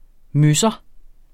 Udtale [ ˈmøsʌ ]